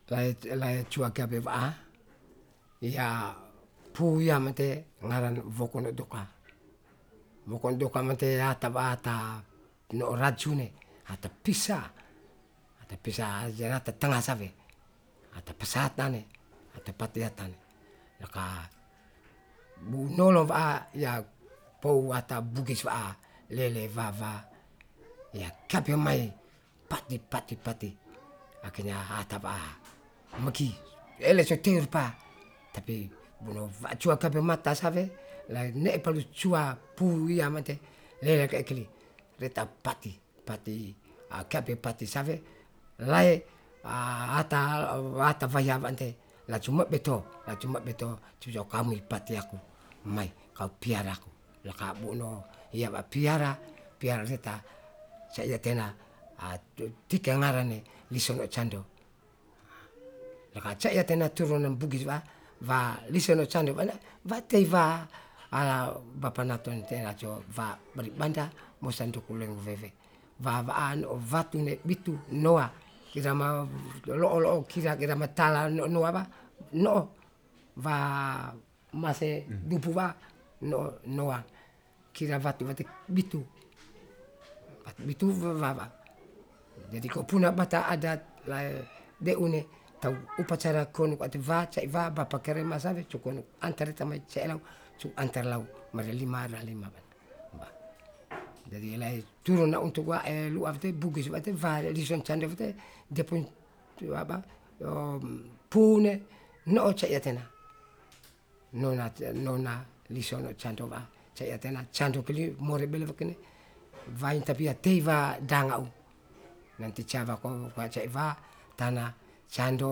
Interviewer
Genre: Legend/Ancestor history.
Recording made in kampong/domain Woto.